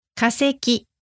noun | かせき